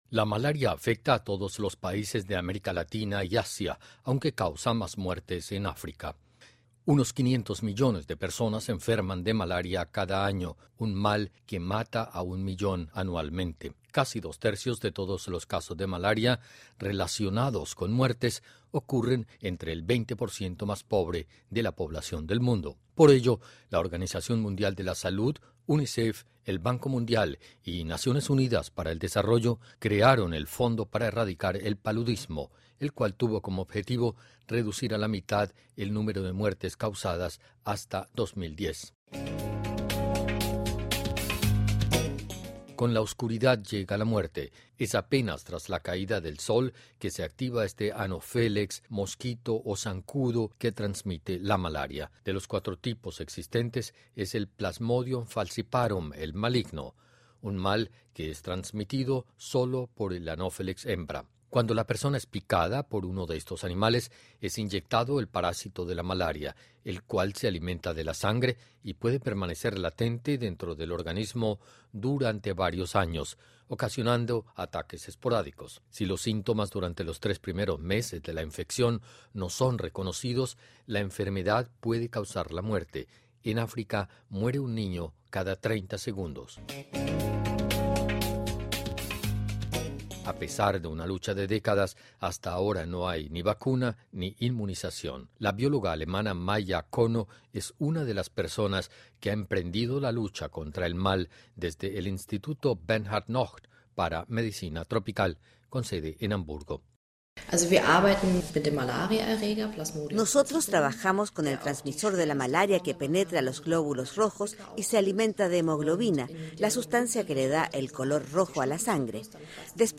Unas 500 millones de personas se enferman de malaria cada año y un millón mueren. Escuche el informe de la Deutsche Welle, que repasa la lucha contra este mal.